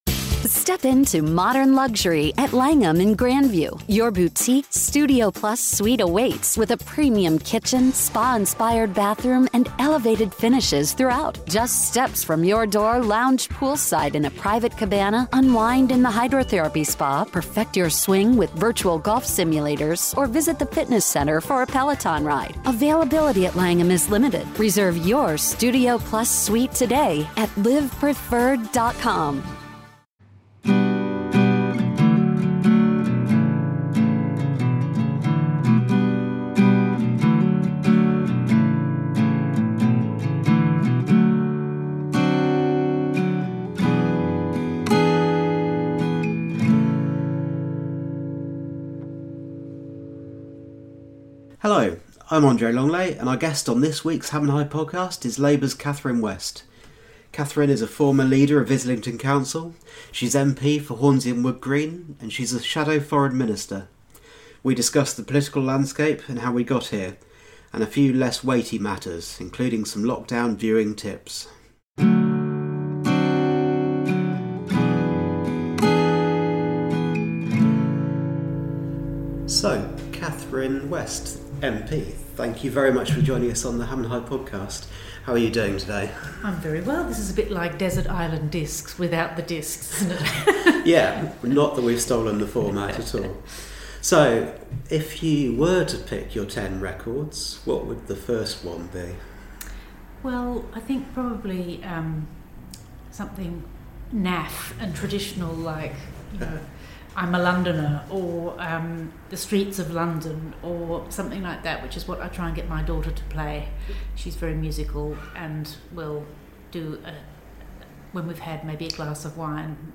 Each week the Ham & High Podcast interviews a different guest about their life and career.